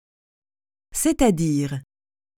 Pronunciation
/sɛ.ta.diʁ/
4.1-c-est-a-dire.mp3